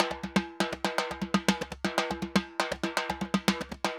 Timba_Candombe 120_1.wav